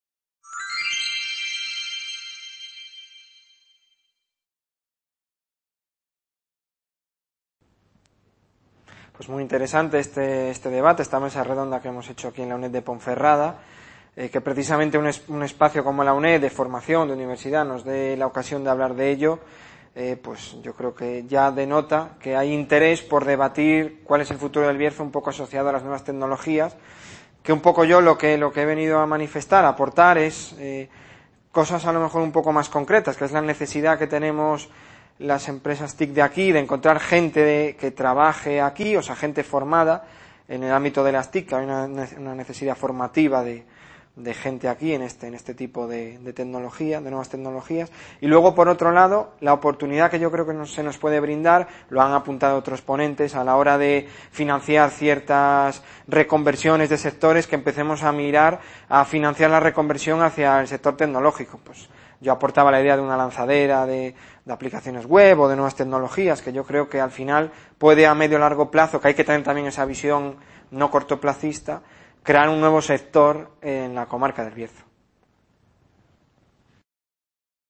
VI Edición del Festival Villar de los Mundos - El PASADO de LOS BARRIOS y el FUTURO de EL BIERZO
Video Clase